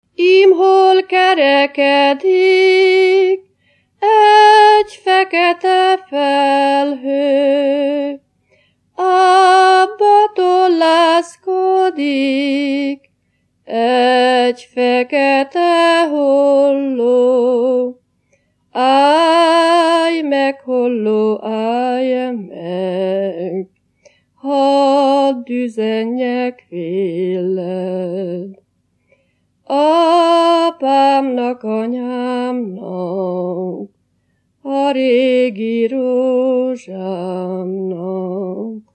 Erdély - Szolnok-Doboka vm. - Magyardécse
Műfaj: Virágének
Stílus: 4. Sirató stílusú dallamok
Kadencia: 5 (4) 1 1